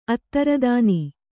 ♪ attaradāni